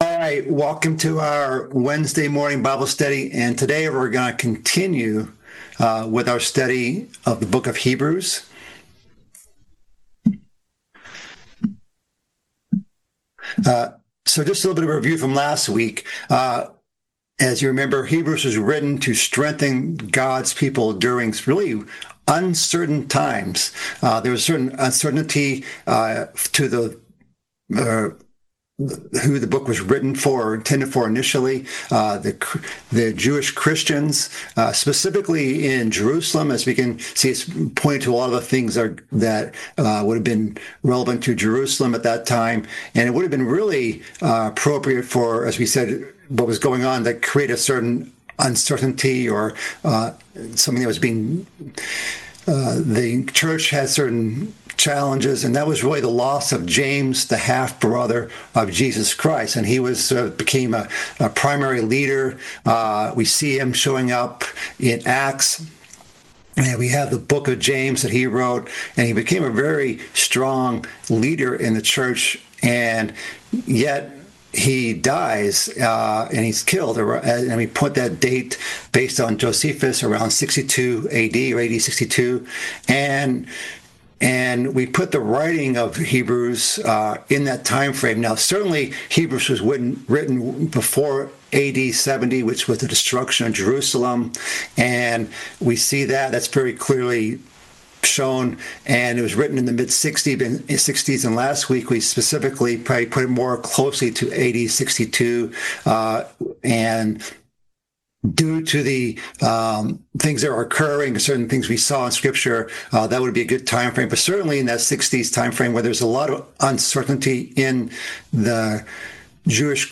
Bible Study - Hebrews Part 2 - Introduction & 1:1-3